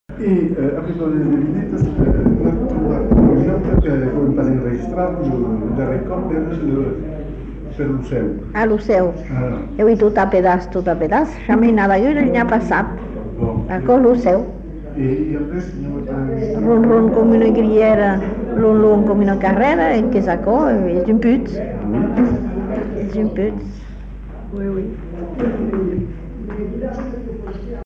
Aire culturelle : Bazadais
Lieu : Bazas
Effectif : 1
Type de voix : voix de femme
Production du son : récité
Classification : devinette-énigme